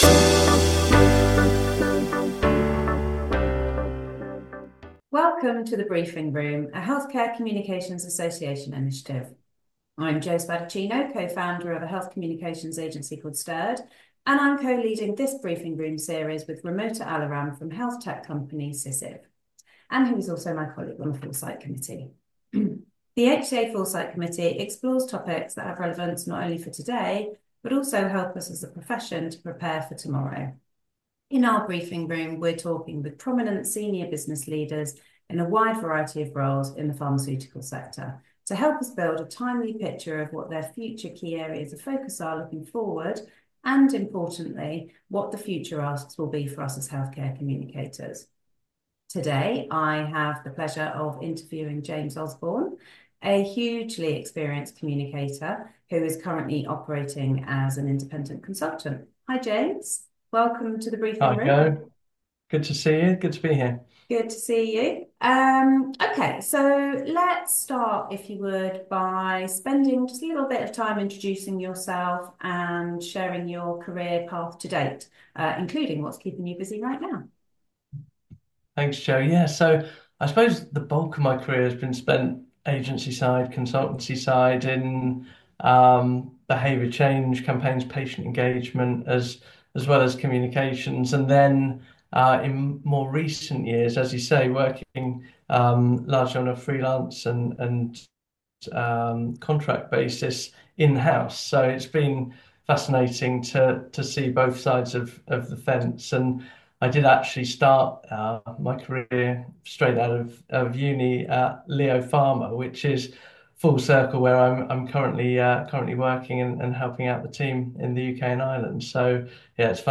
What You’ll Discover in This Interview The ethics of AI-generated patients – innovation vs. authenticity in patient engagement.